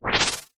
spin.ogg